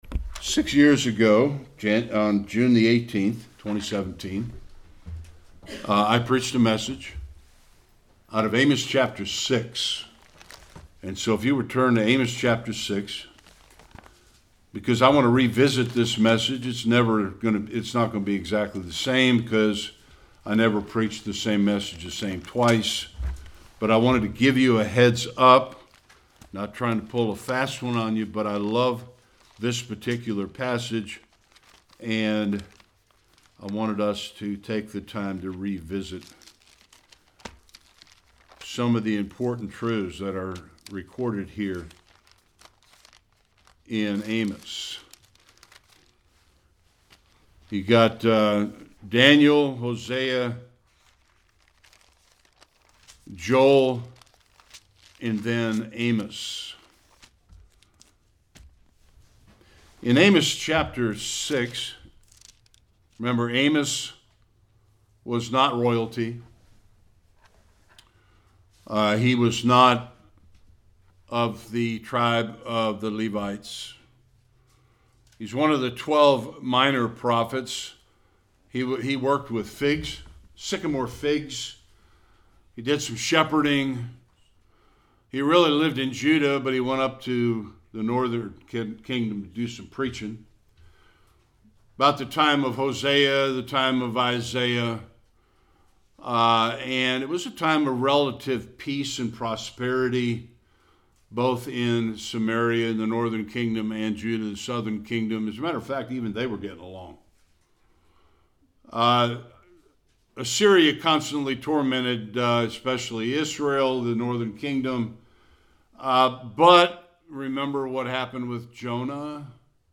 1-7a Service Type: Sunday Worship We are all leading someone.